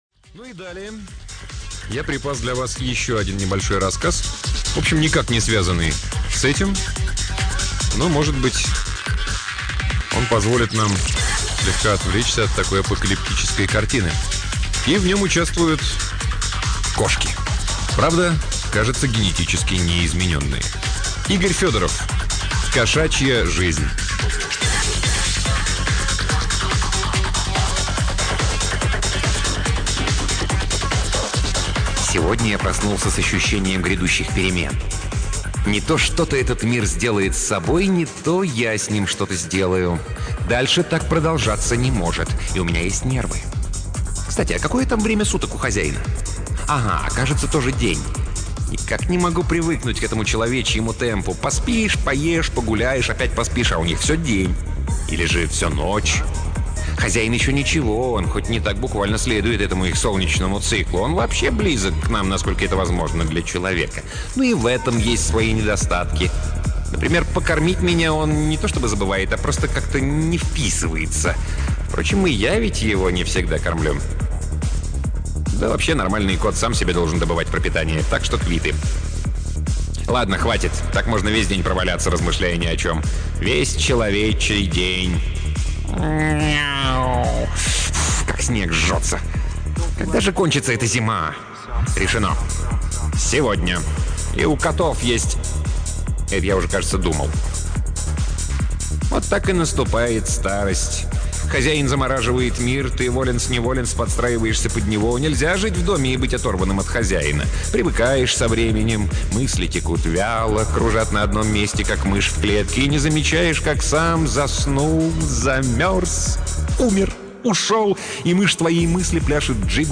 Аудиокнига Игорь Федоров — Кошачья жизнь